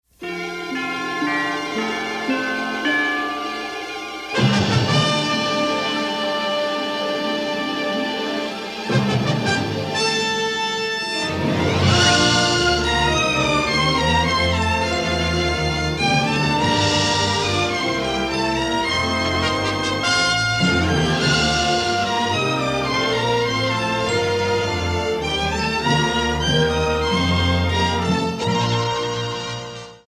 rousing orchestral work